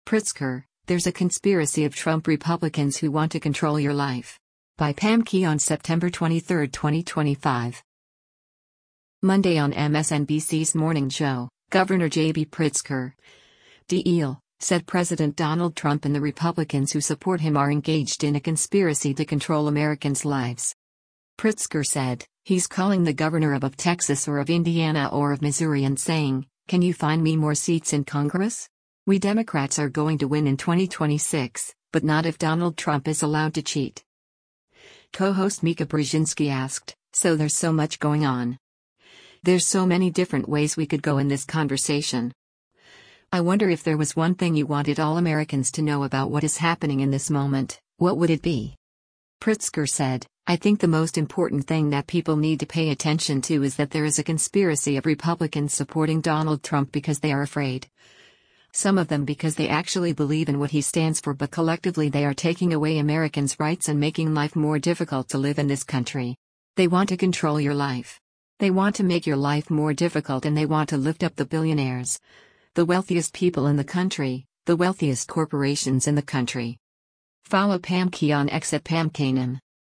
Monday on MSNBC’s “Morning Joe,” Gov. JB Pritzker (D-IL) said President Donald Trump and the Republicans who support him are engaged in a “conspiracy” to control Americans’ lives.